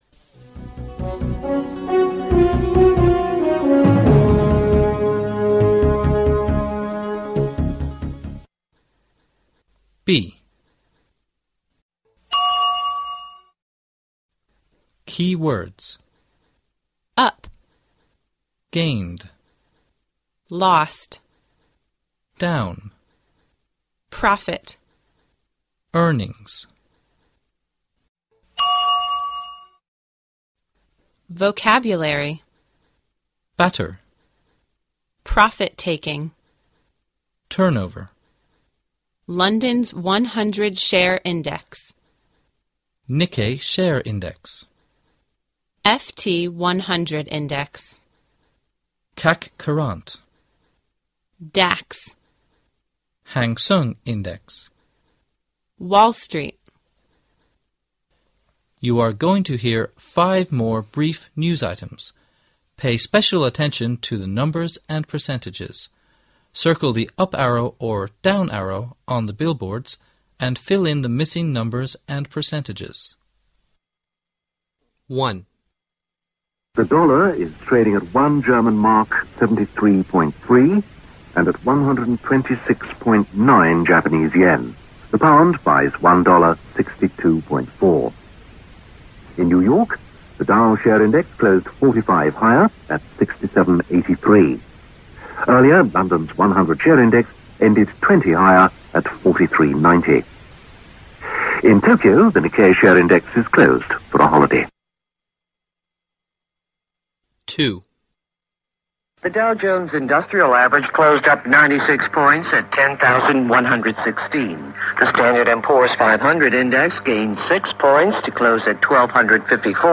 You're going to hear 5 more brief news items.